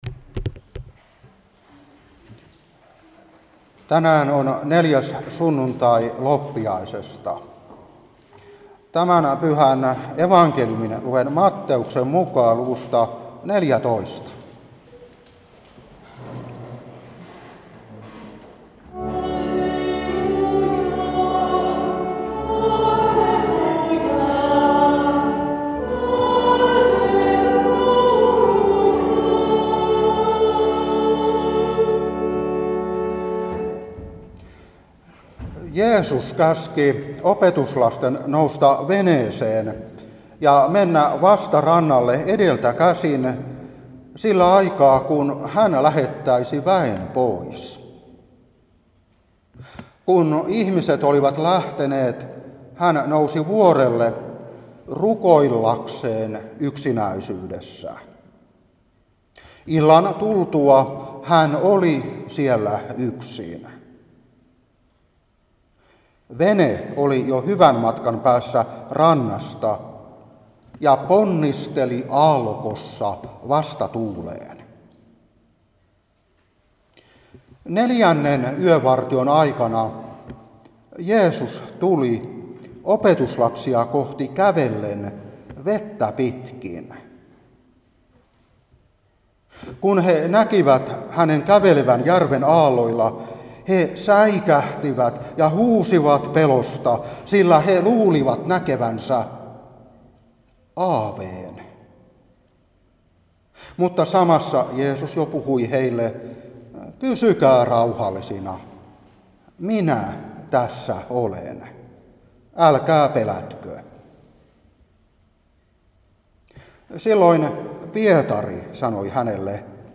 Saarna 2023-1.